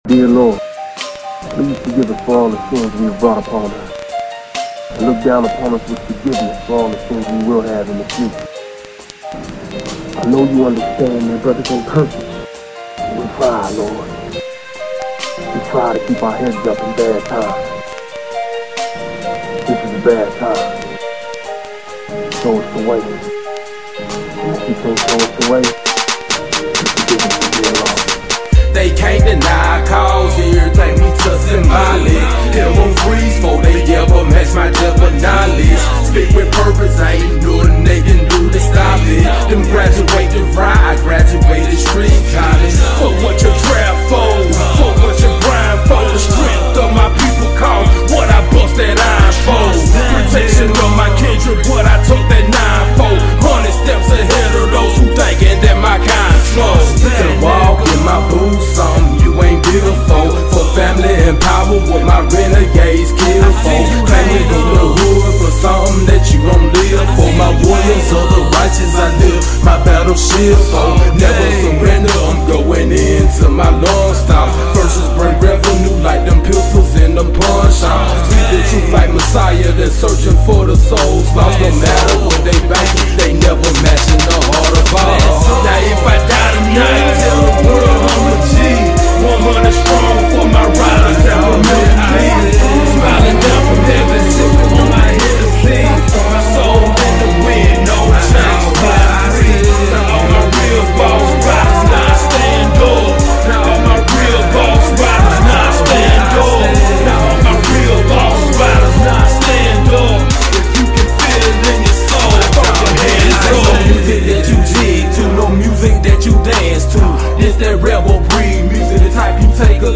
Genre: Gangsta Rap.